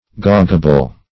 Meaning of gaugeable. gaugeable synonyms, pronunciation, spelling and more from Free Dictionary.
Gaugeable \Gauge"a*ble\, a.